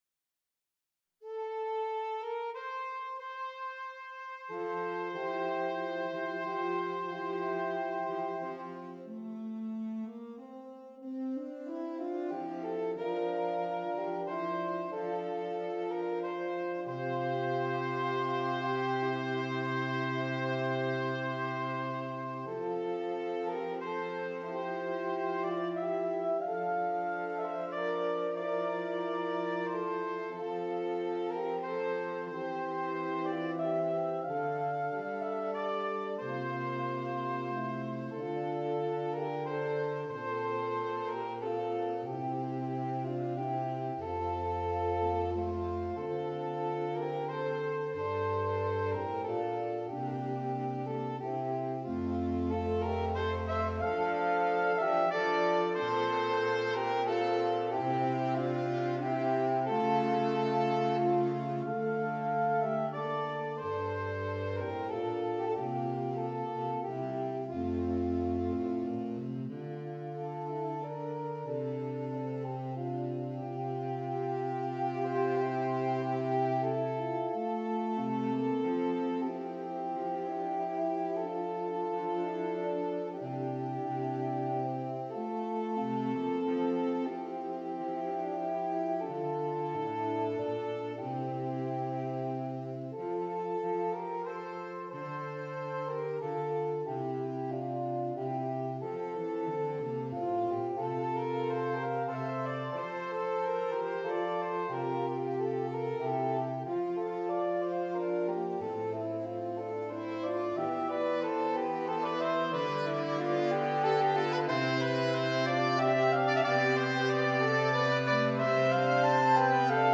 Saxophone Quartet (SATB)
Traditional Spanish Carol